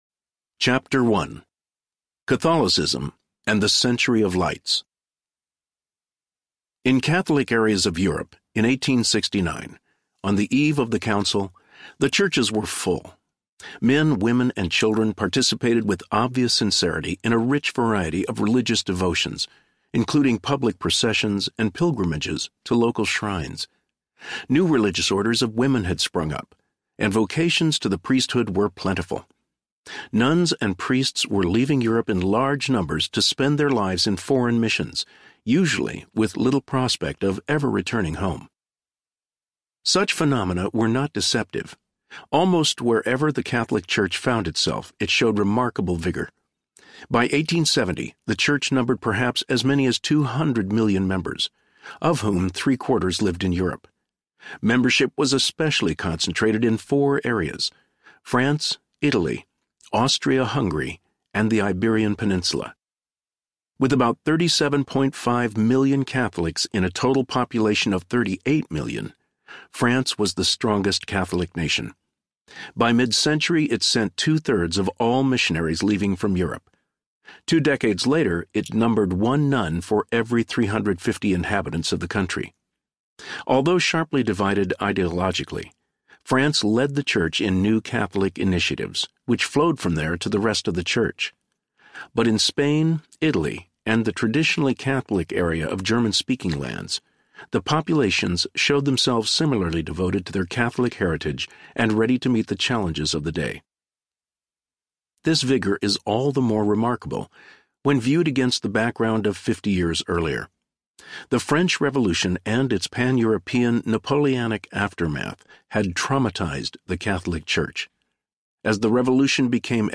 Vatican I Audiobook
Narrator
8.3 Hrs. – Unabridged